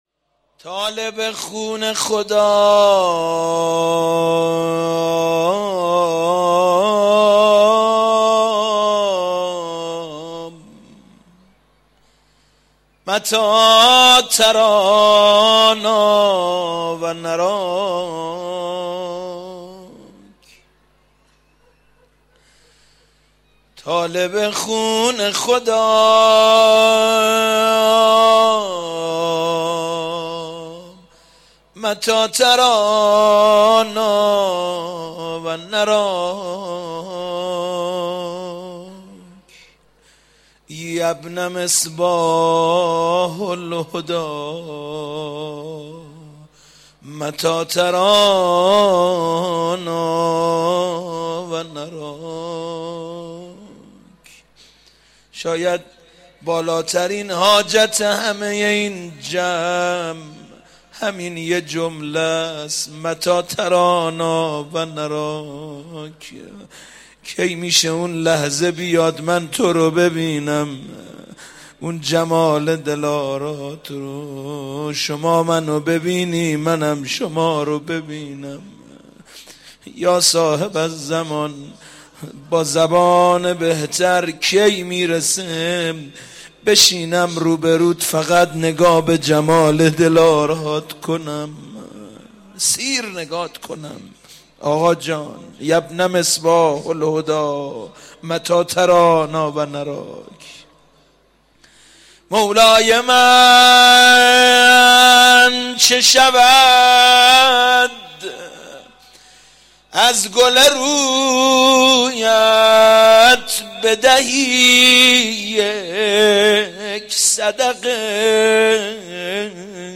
مراسم دعای توسل در تاریخ 94/04/30 در مسجد مقدس جمکران